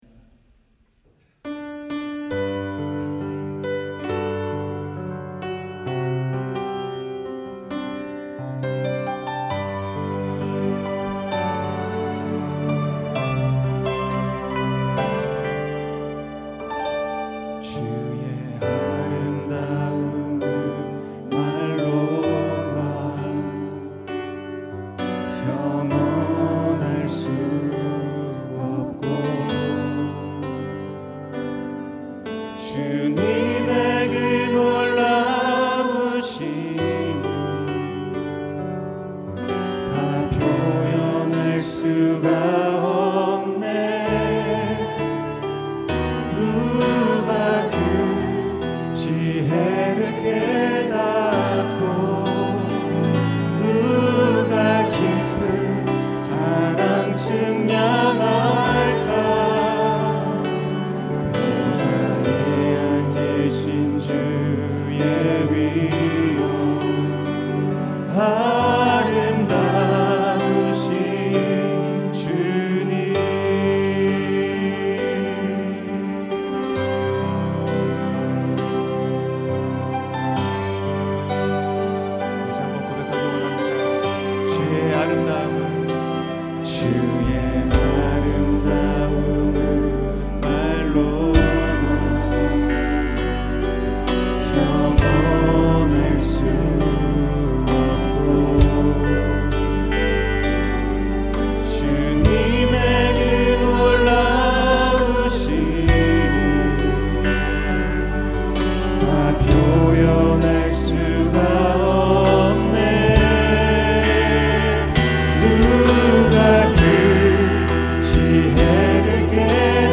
찬양 음악